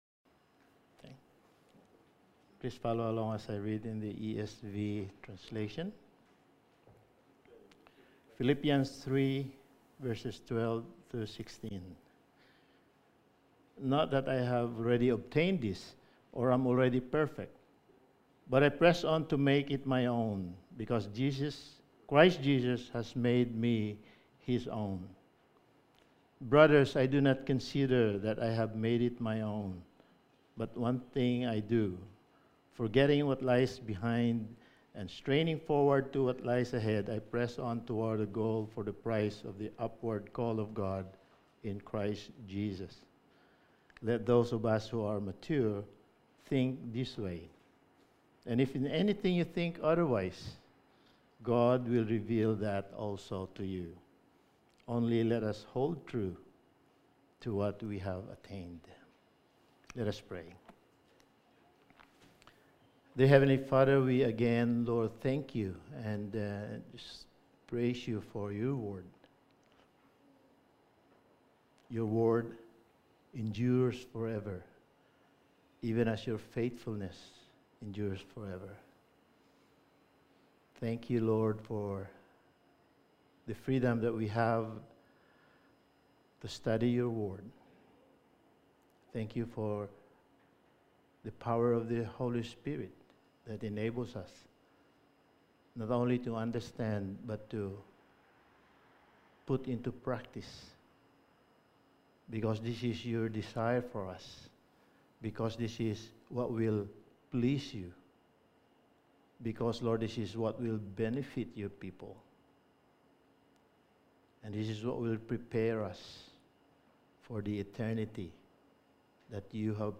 Passage: Philippians 3:12-16 Service Type: Sunday Morning